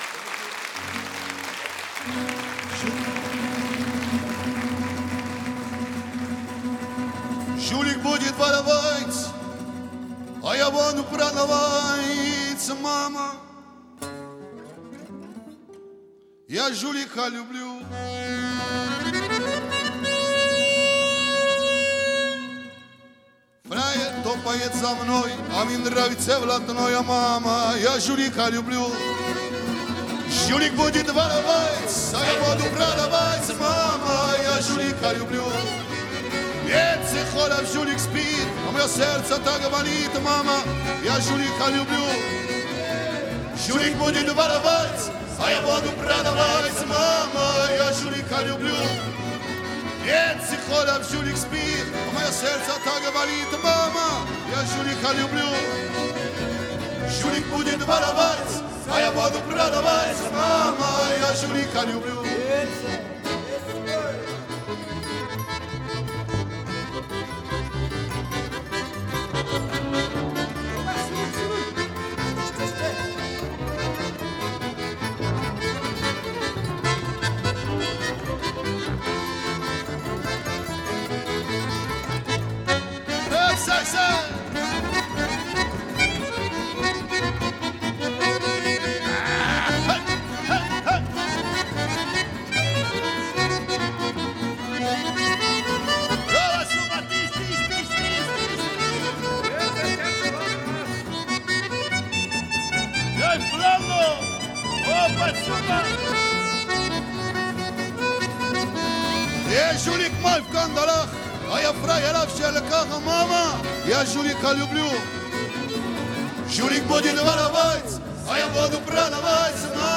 其音乐不仅延续了东欧音乐和“茨冈爵士”的神韵，